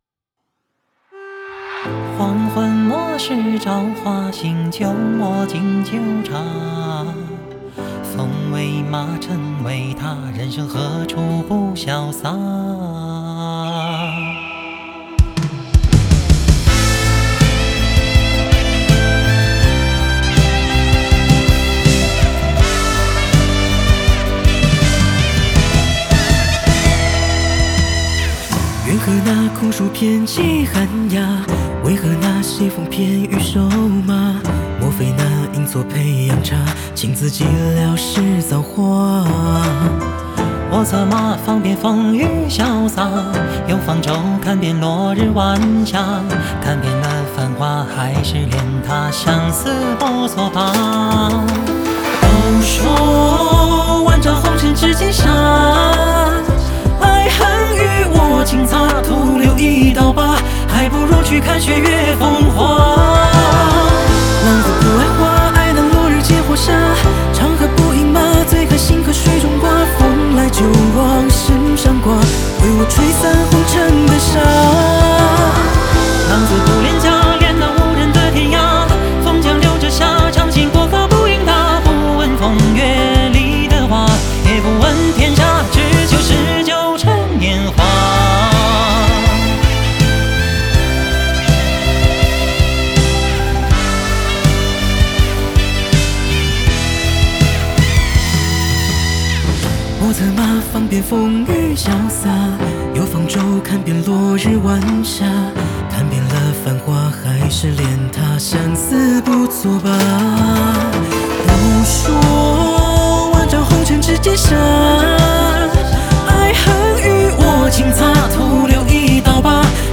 Ps：在线试听为压缩音质节选，体验无损音质请下载完整版 桃李春风酒为马，红泥炉火诗为家。